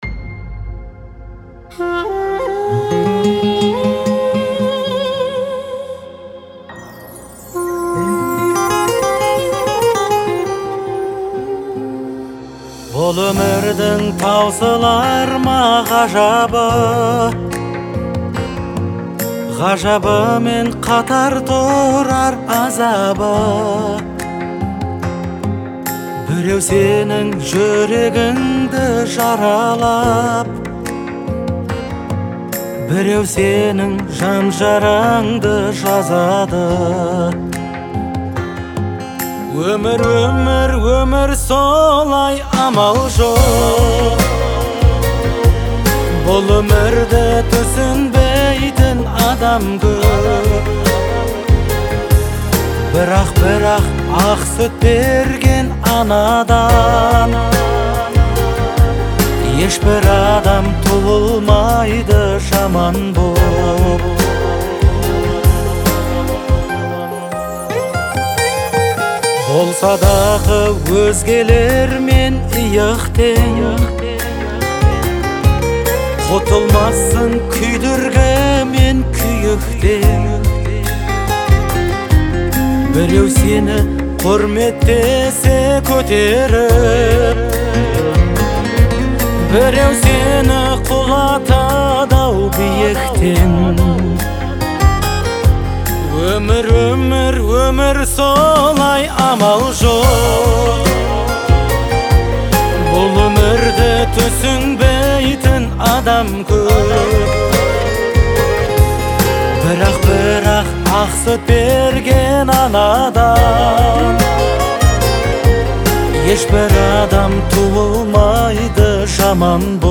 это трогательная песня в жанре казахского поп-фолка